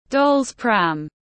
Xe đẩy búp bê tiếng anh gọi là doll’s pram, phiên âm tiếng anh đọc là /ˌdɒlz ˈpræm/
Doll’s pram /ˌdɒlz ˈpræm/